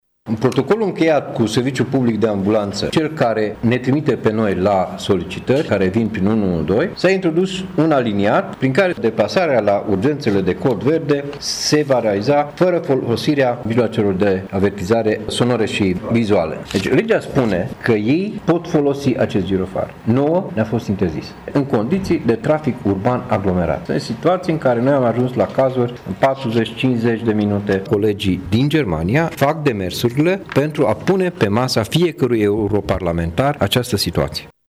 într-o conferință de presă susținută la Tîrgu-Mureș